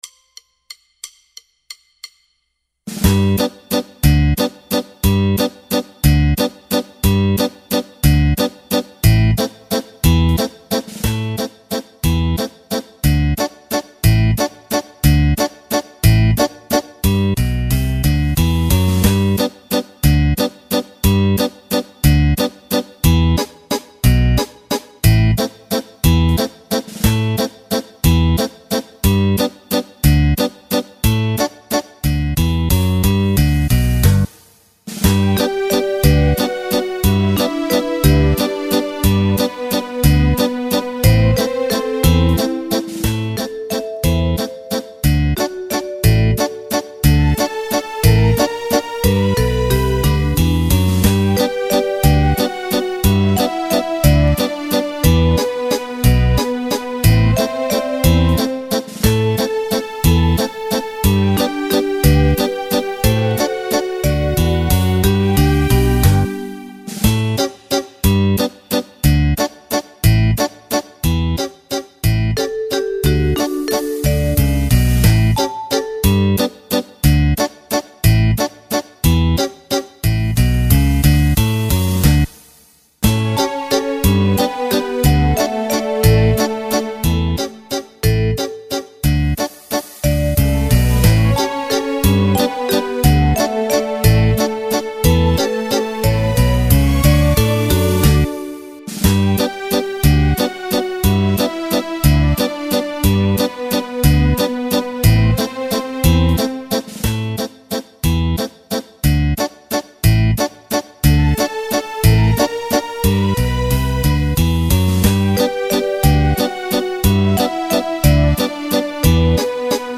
Base MP3